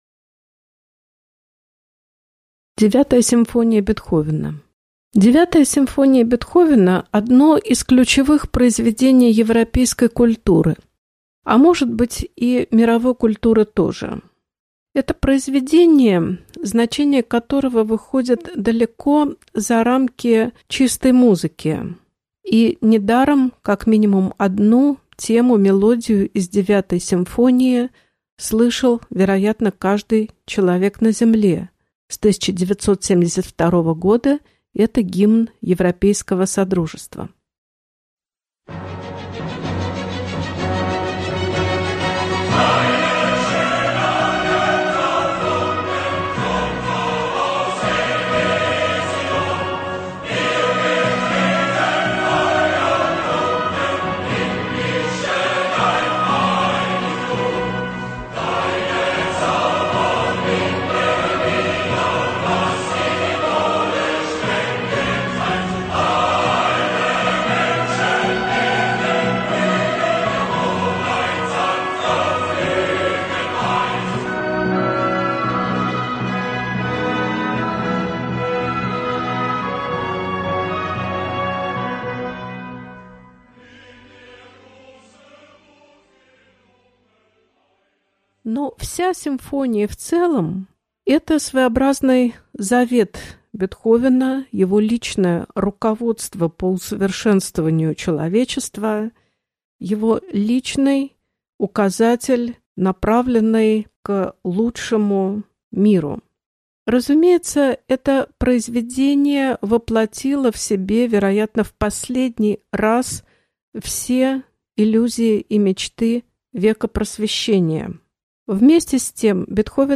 Аудиокнига Лекция «Девятая симфония» | Библиотека аудиокниг